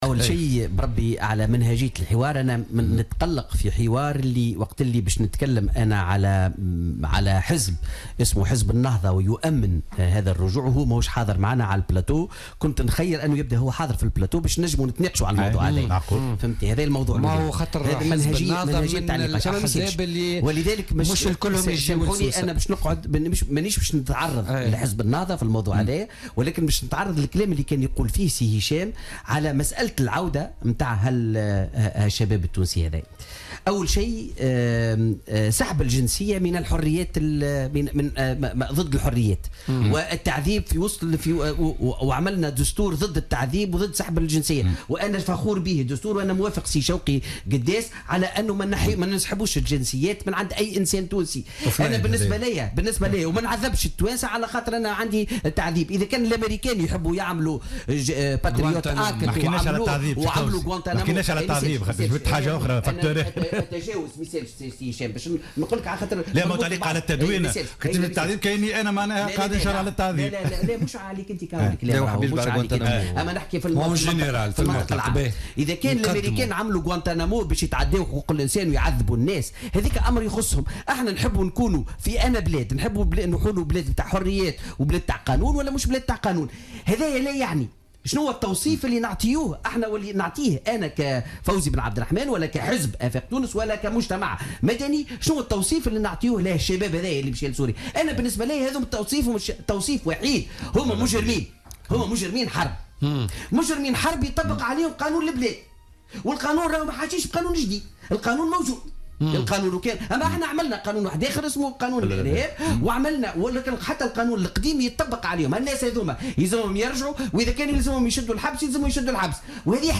اعتبر فوزي عبد الرحمان عن حزب آفاق تونس ضيف برنامج بوليتكا لليوم الخميس 15 ديسمبر 2016 أن سحب الجنسية التونسية من الإرهابيين الذين كانوا يقاتلون في بؤر التوتر والذين ينوون العودة إلى تونس ضد الحريات وضد الدستور.